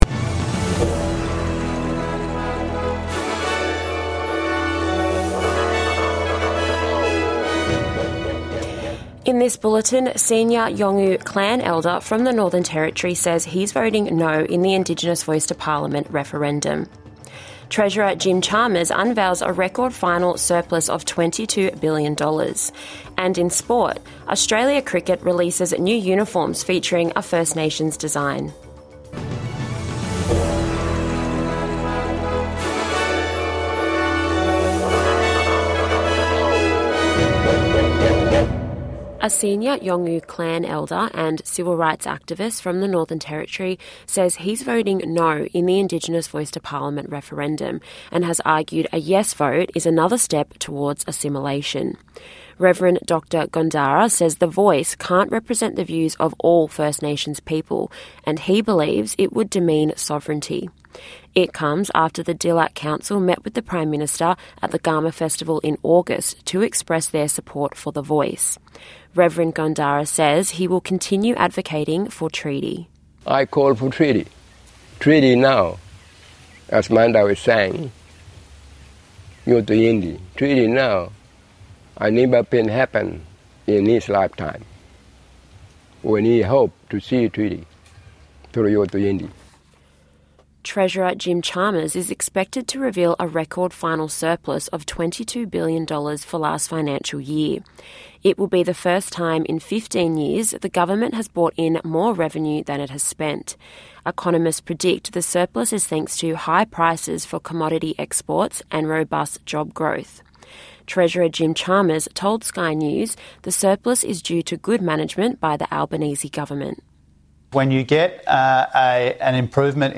NITV Radio - News 22/09/2023